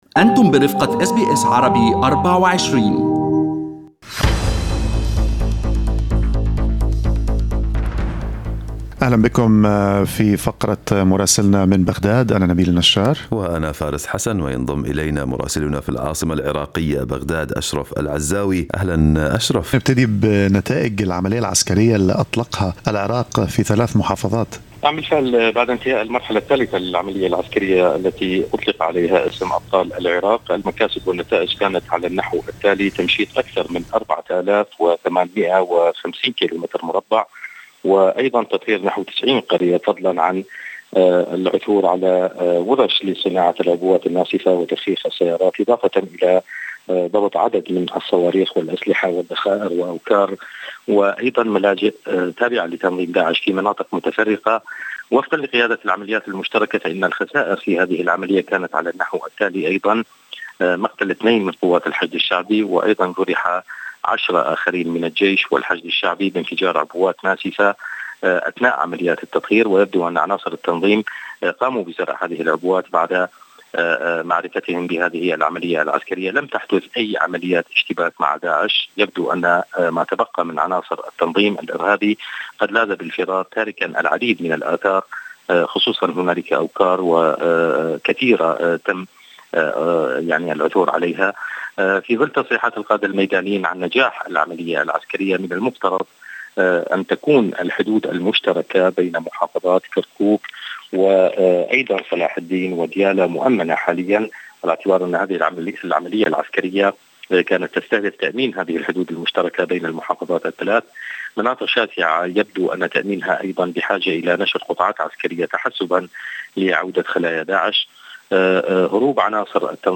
من مراسلينا: أخبار العراق في أسبوع 26/06/2020